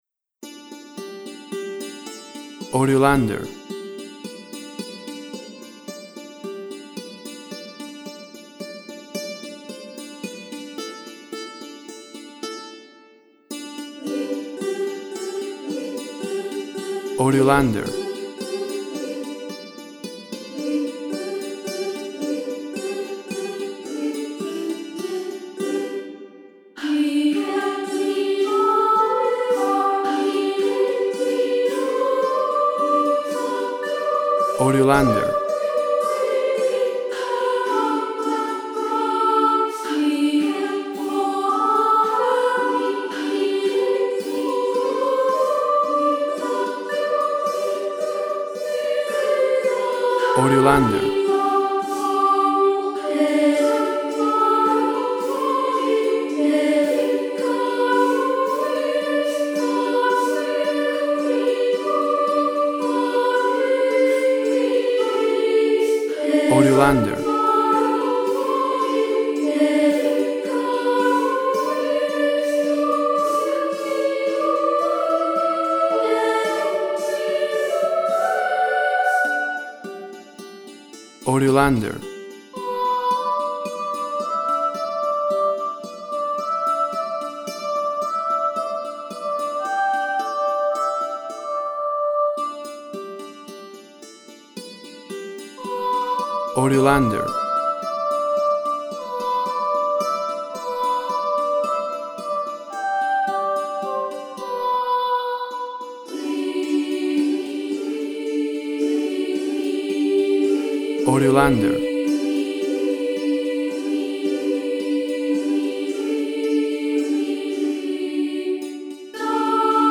A bright and cheerful song with boys choir and dulcimer.
Tempo (BPM) 108